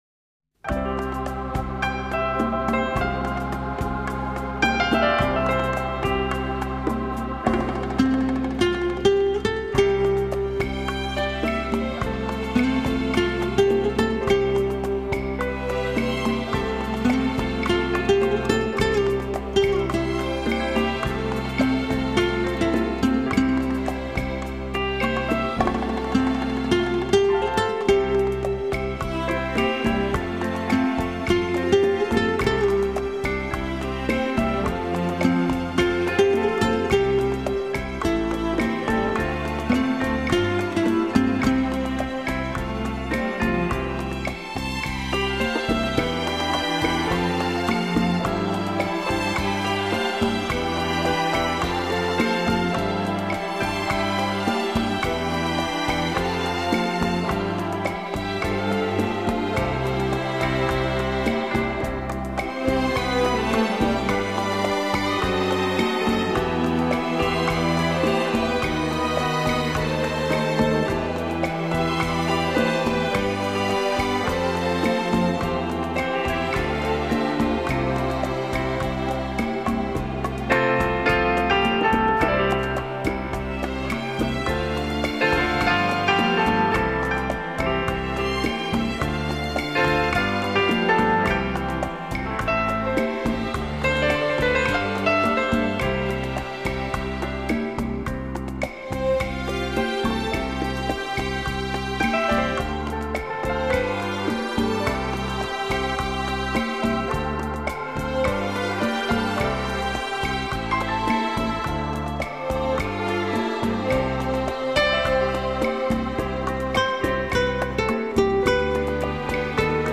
Genre: Ballroom Dance
(Rumba)